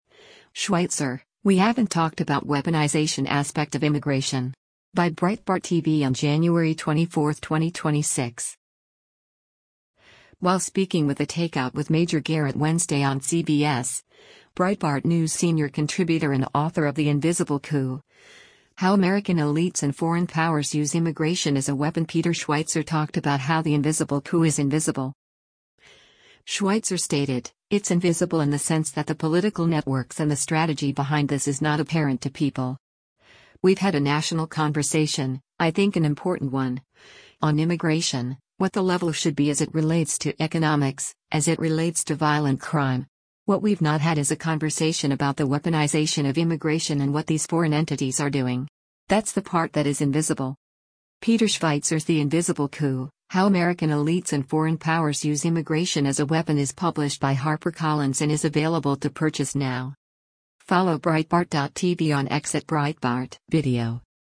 While speaking with “The Takeout with Major Garrett” Wednesday on CBS, Breitbart News senior contributor and author of The Invisible Coup: How American Elites and Foreign Powers Use Immigration as a Weapon Peter Schweizer talked about how the invisible coup is invisible.